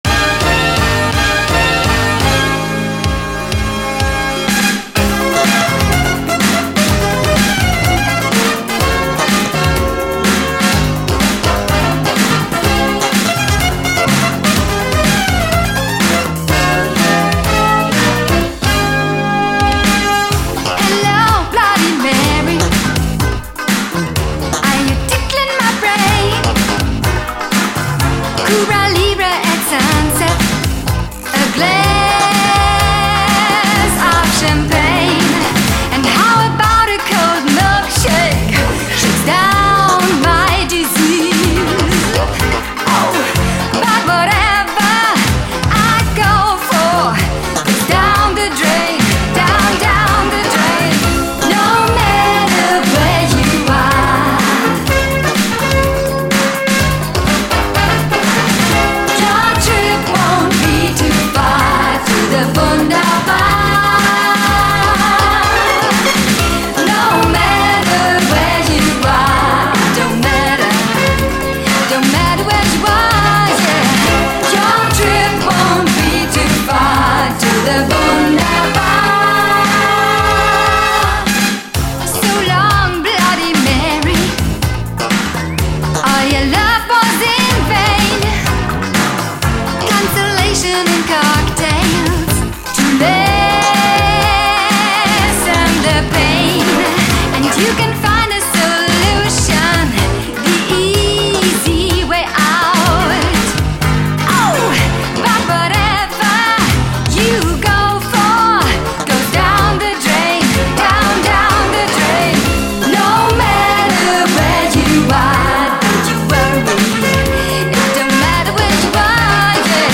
SOUL, 70's～ SOUL, DISCO, 7INCH
ファンタジックなシンセが突き抜ける、魅惑の最高キャッチー・シンセ・ブギー！
ファンタジックなシンセの突き抜けっぷりに仰け反る最高キャッチー・シンセ・ブギー！